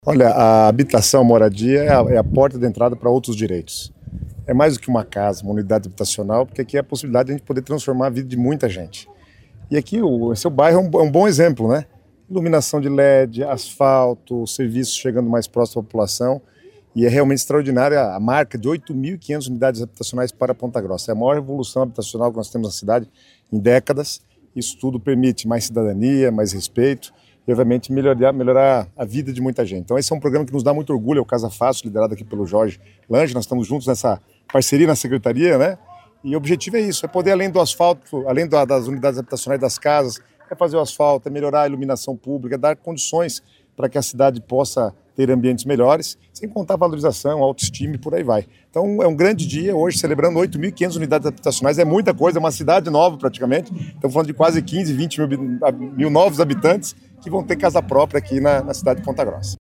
Sonora do secretário das Cidades, Guto Silva, sobre a entrega de 220 casas em Ponta Grossa